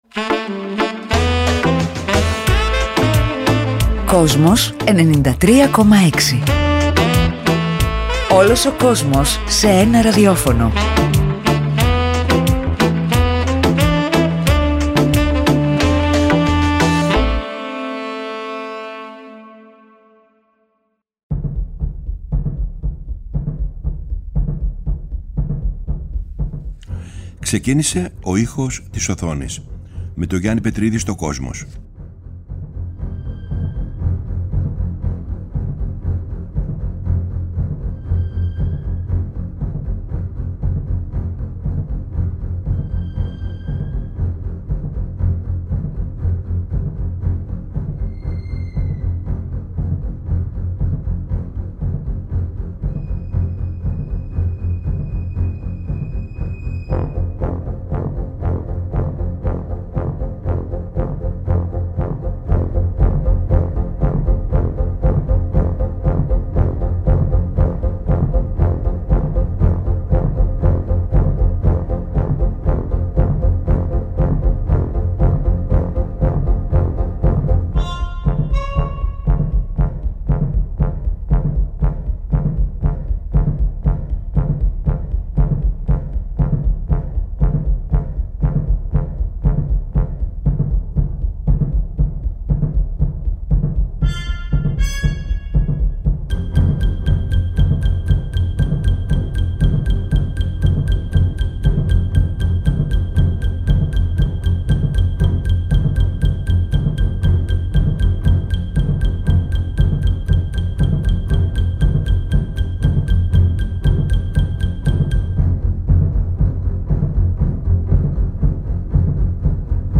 Στον σημερινό ήχο της οθόνης ακούσαμε μουσική